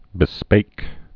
(bĭ-spāk)